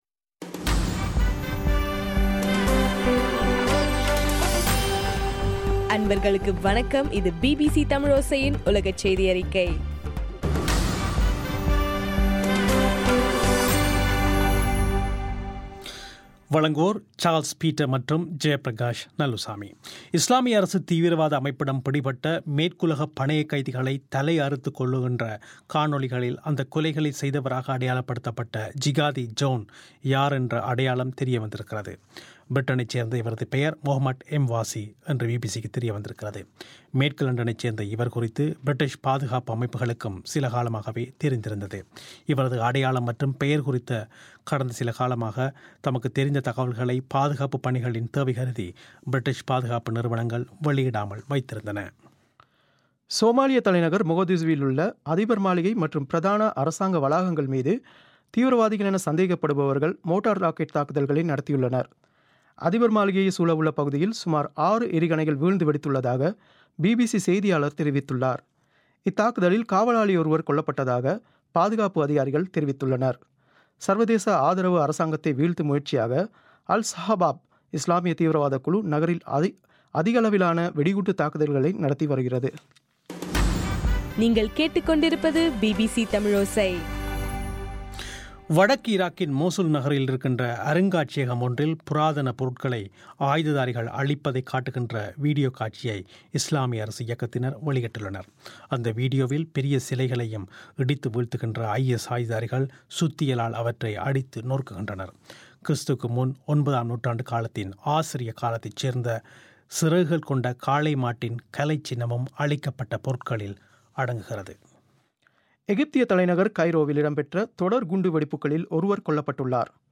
இன்றைய ( பிப்ரவரி 26) பிபிசி தமிழோசை செய்தியறிக்கை